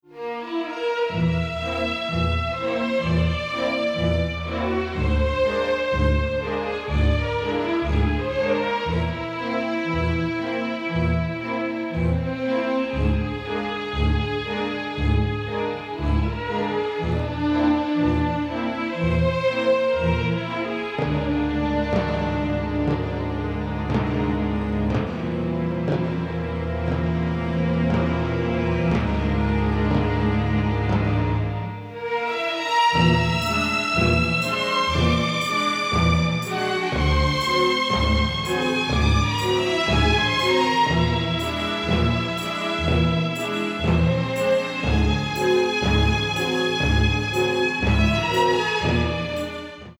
mastered from the original tapes